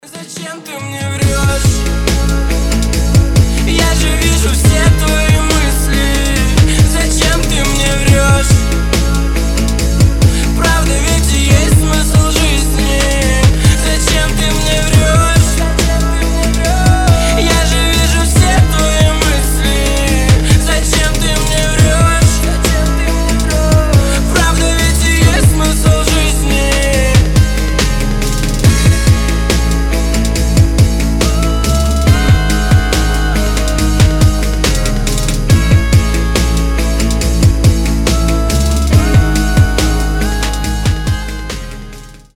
• Качество: 320, Stereo
гитара
лирика
грустные
красивый мужской голос